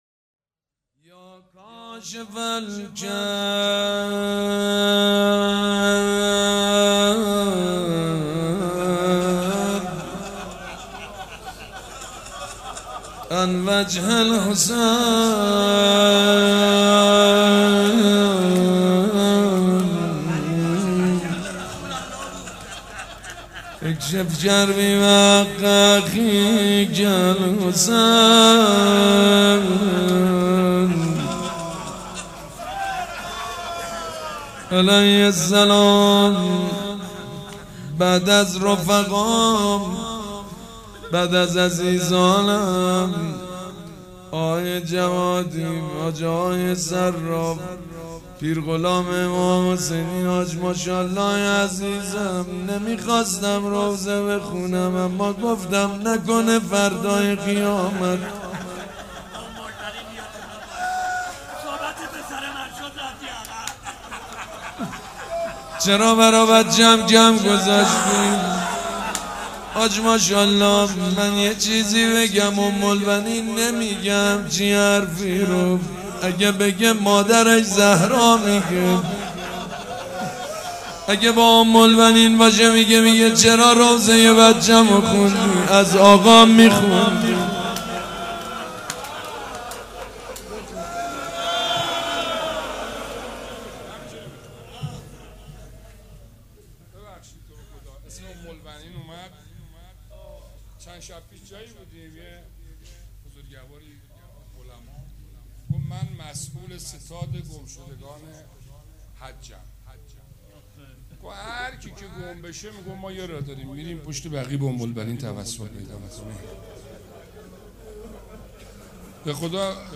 روضه بخش دوم مراسم عزاداری شب چهارم صفر
روضه
مراسم عزاداری شب چهارم